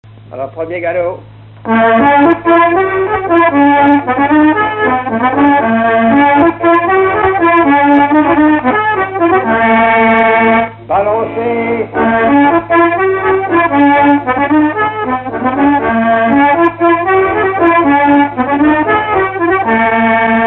Mémoires et Patrimoines vivants - RaddO est une base de données d'archives iconographiques et sonores.
Résumé instrumental
danse : quadrille : galop
Pièce musicale inédite